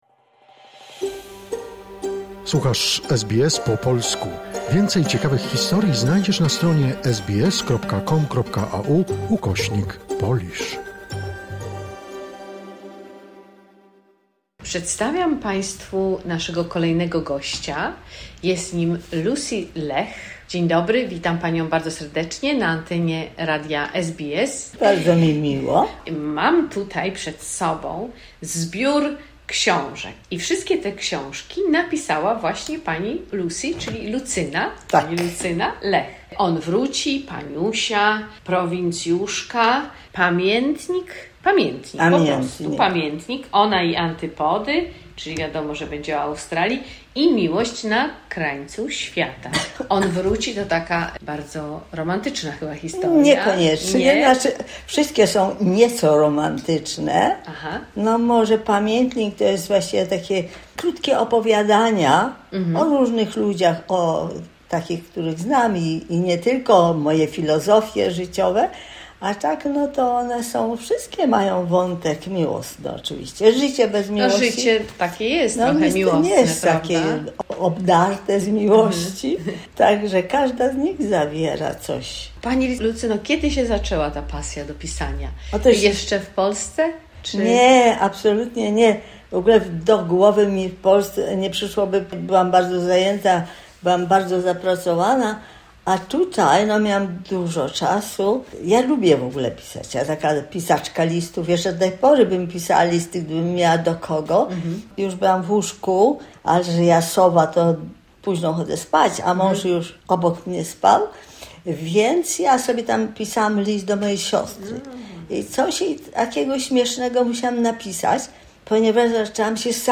Pierwsza część rozmowy